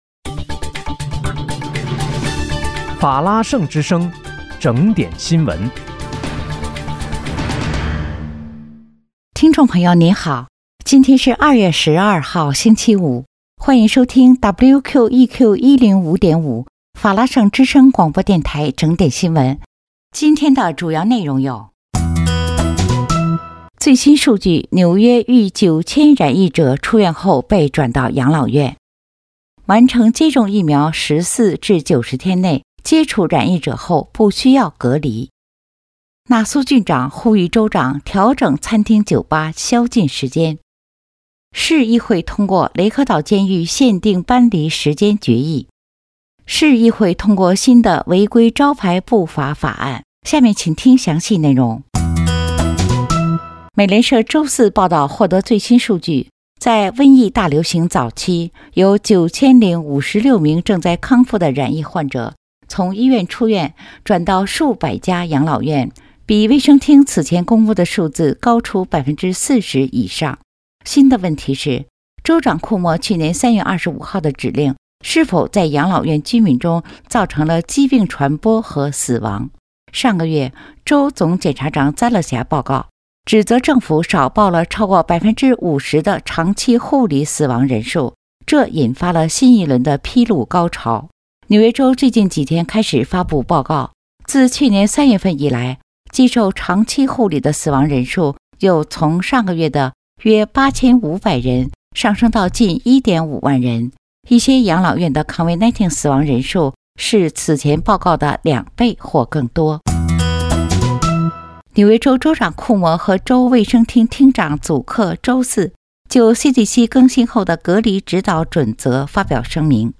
2月12日（星期五）纽约整点新闻